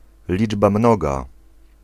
Ääntäminen
Synonyymit multiple several manifold morefold Ääntäminen US : IPA : [ˈplʊɹ.əl] Tuntematon aksentti: IPA : /ˈplʊə.ɹəl/ IPA : /ˈplɔː.ɹəl/ Lyhenteet ja supistumat pl. pl